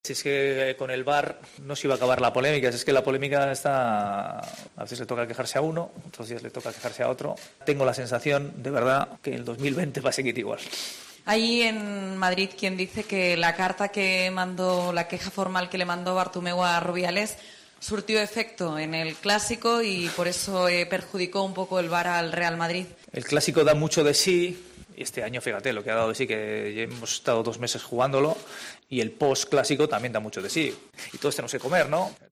"Se nos olvida a veces que el VAR está para ayudar, pero el que arbitra es el árbitro", recordó el técnico azulgrana en sala de prensa.